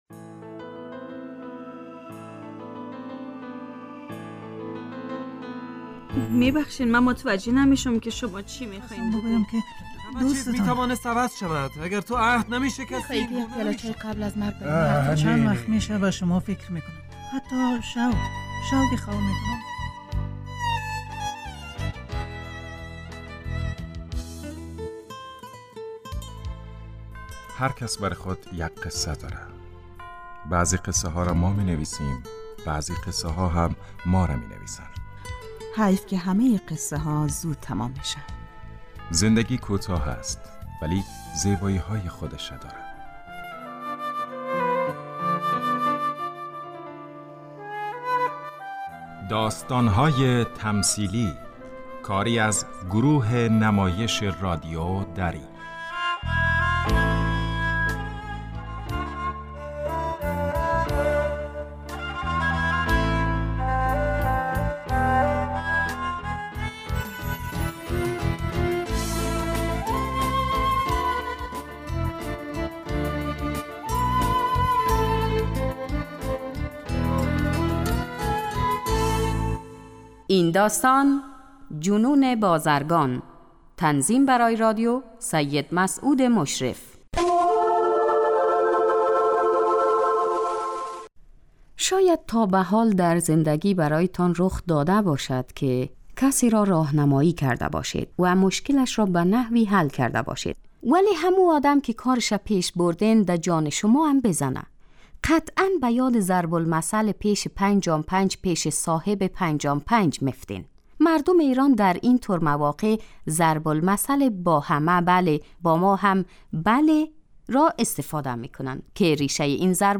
داستان تمثیلی / جنون بازرگان